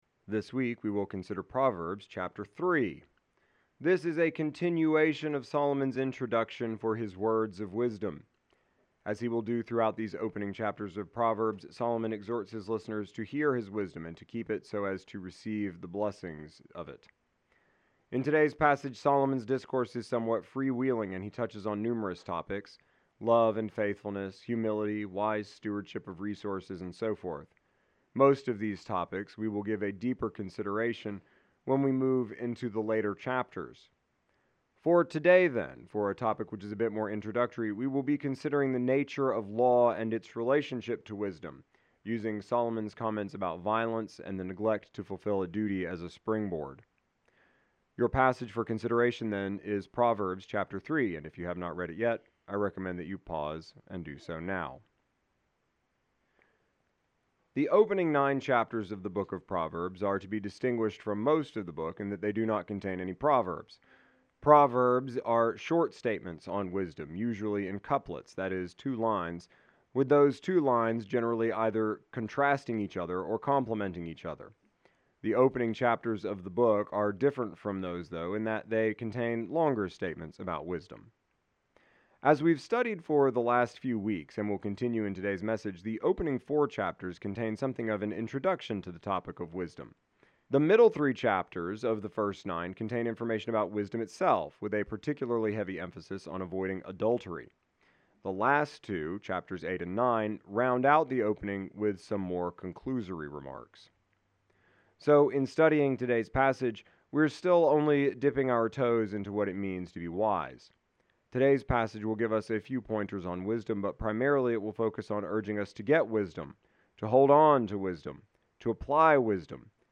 exegetical sermon series